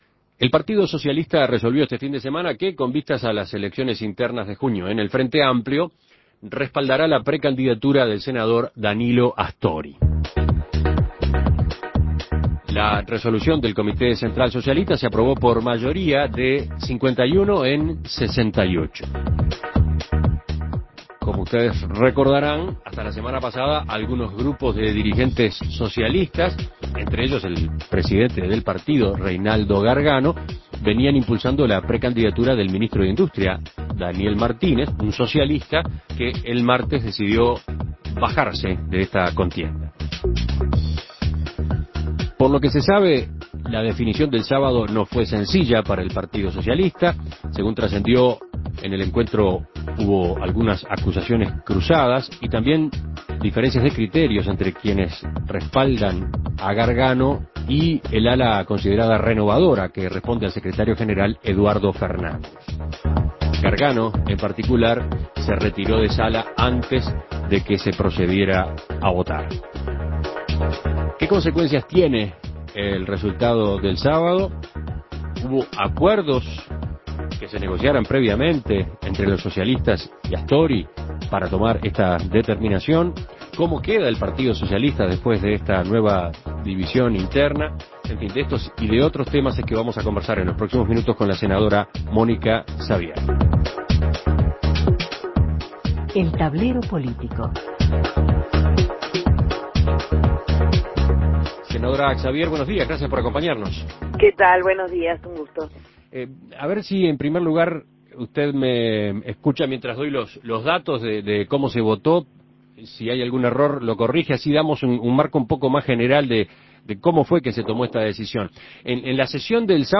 Escuche la entrevista a Mónica Xavier